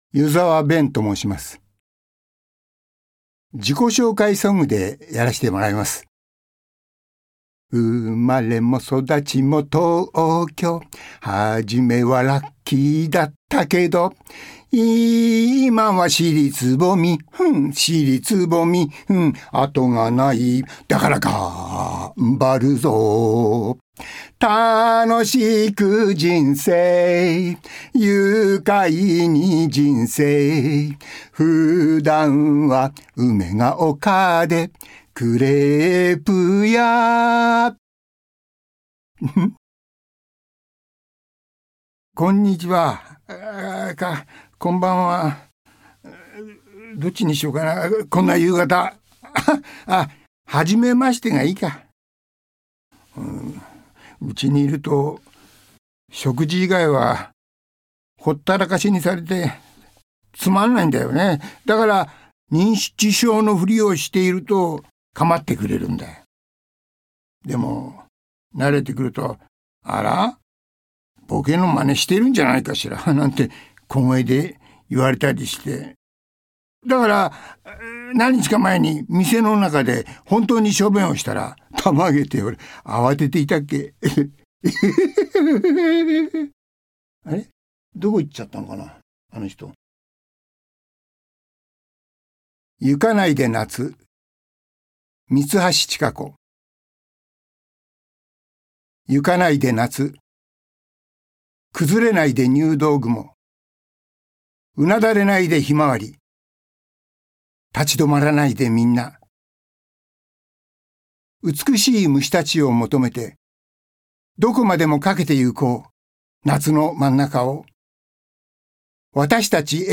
属性：voice
ボイスサンプル、その他